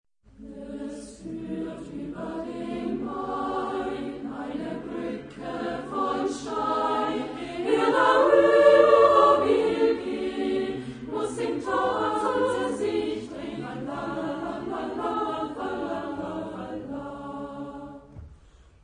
Genre-Style-Form: ballet ; Madrigal ; Secular ; Popular
Type of Choir: TTBB  (4 men voices )
Tonality: D minor